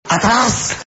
AUDIO: La palabra del Cigala que usó en Antena 3 contando una anécdota en El Hormiguero.